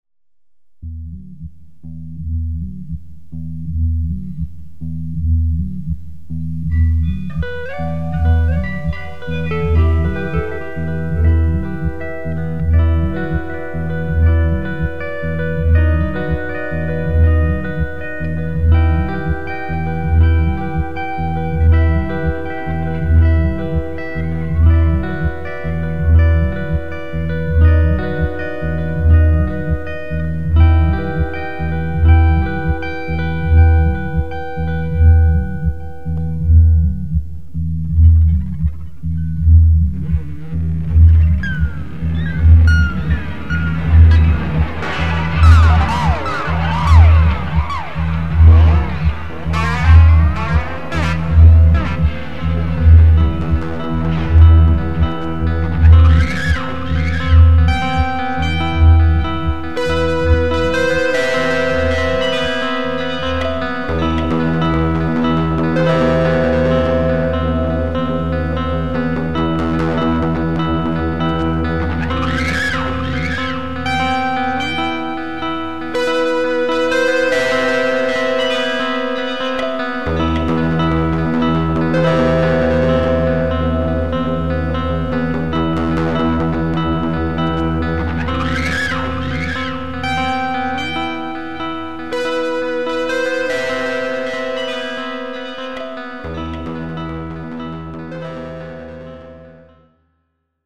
Estilo: Post rock-punk